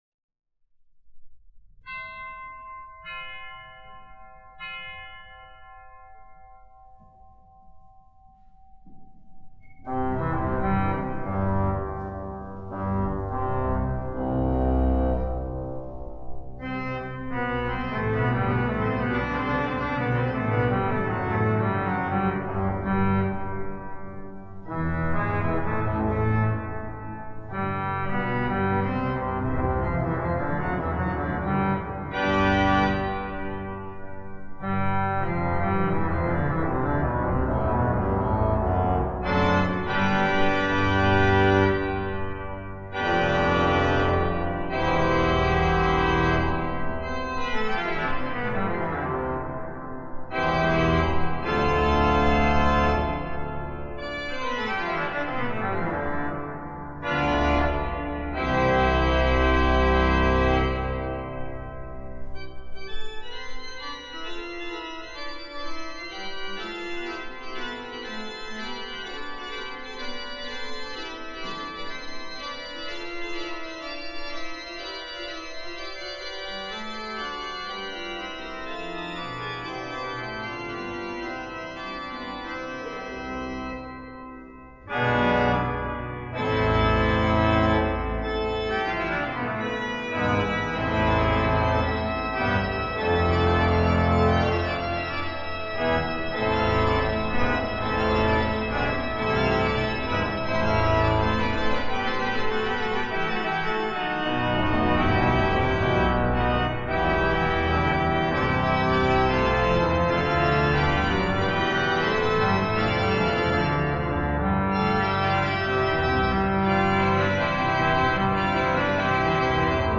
Seit nunmehr dreihundert Jahren findet die Liturgie mit die-sem längsovalen Kuppelraum und seinem Ausstattungsambi-ente einen würdigen, prächtigen Rahmen mit singulärer Top-Akustik.
Daher ist gerade diese Orgel in ihrem gewachsenen Zustand so bemerkenswert, da just dieser imperfekte status quo in dieser Akustik sich zu einem Unikat etablieren konnte.
Klangbeispiel